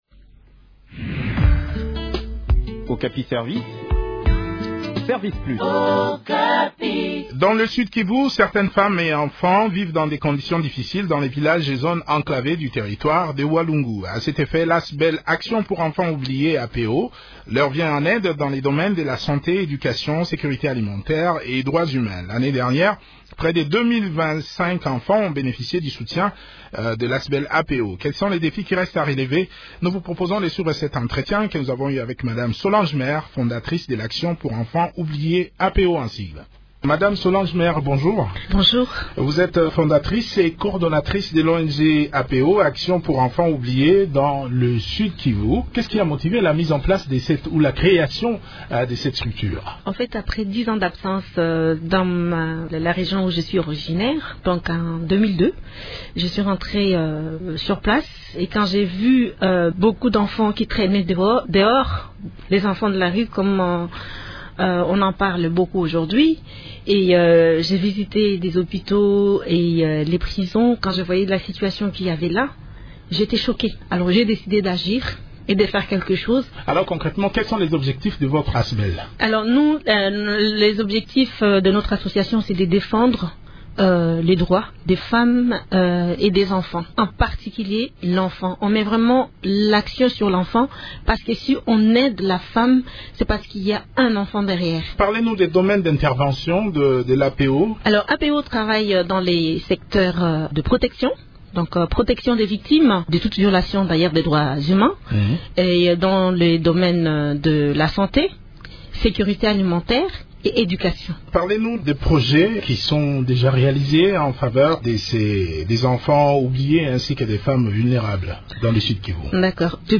Le point sur le programme d’assistance de cette population dans cet entretien